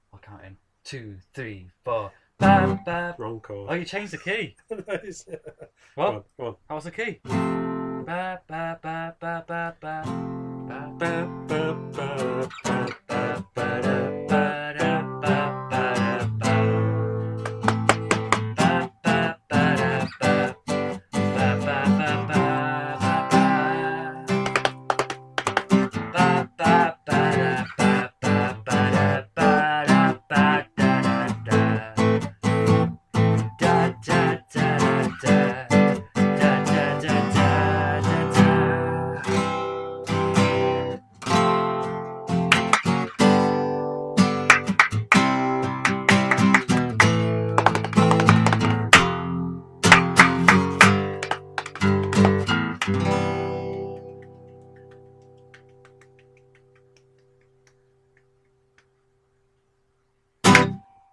Another song reminded us of it, but we can't place it. We're thinking Britpop, indie guitars, possibly strings...